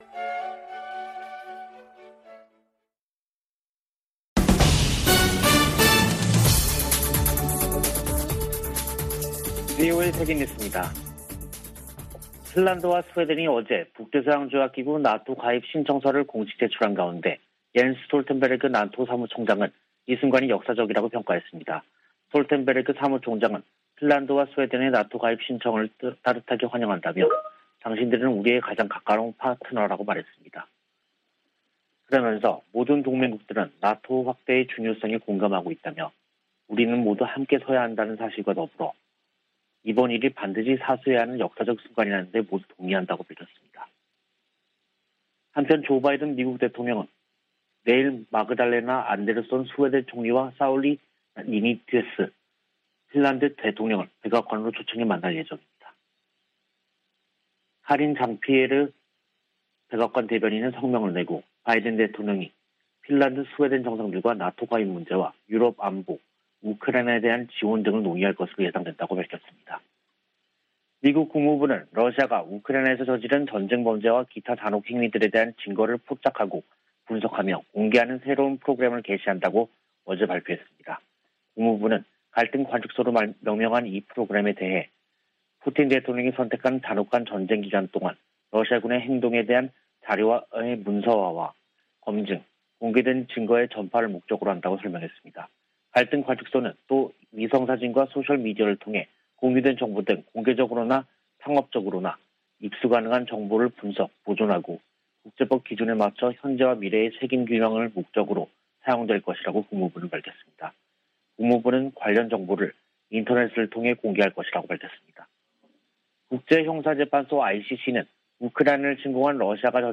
VOA 한국어 간판 뉴스 프로그램 '뉴스 투데이', 2022년 5월 18일 3부 방송입니다. 오는 21일 서울에서 열리는 미한 정상회담에서 북한 핵 위협에 대한 실효적인 확장억제력 강화 방안이 핵심적으로 다뤄질 것이라고 한국 대통령실이 밝혔습니다. 미 국무부는 북한이 코로나바이러스 감염증 확산세 속에서도 핵실험 의지를 꺾지 않을 것이라고 내다봤습니다. 미 의회에 발의된 북한 인권법 연장 법안에 코로나 관련 지원 기조가 처음으로 명기됐습니다.